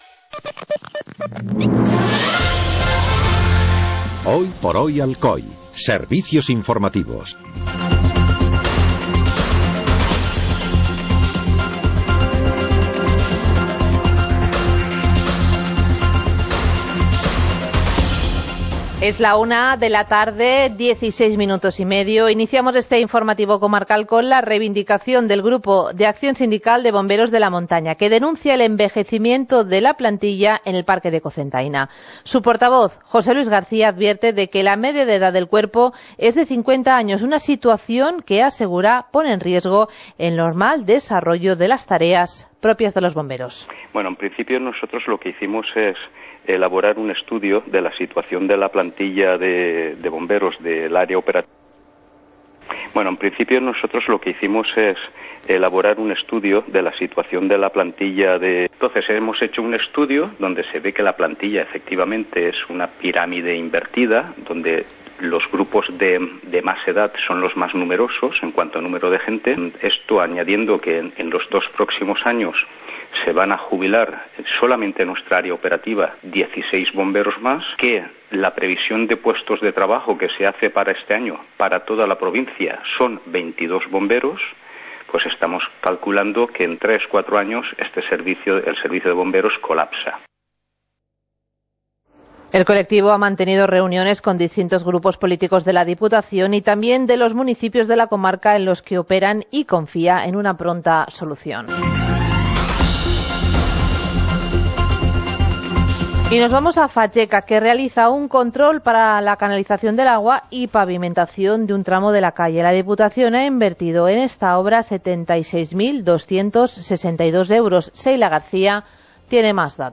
Informativo comarcal - miércoles, 27 de julio de 2016